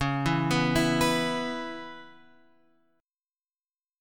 C# Minor 7th